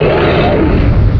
gCryPyroar.wav